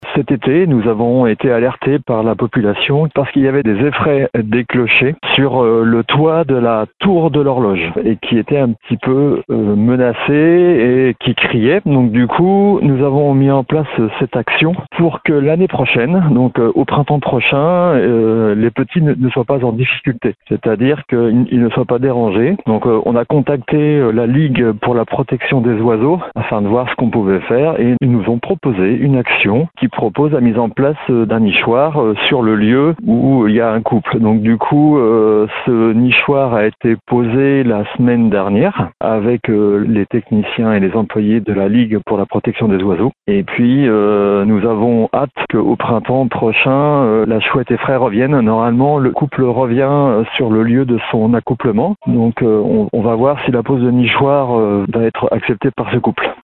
Fabien Blanchet, conseiller municipal en charge du développement durable, nous raconte comment est né ce projet :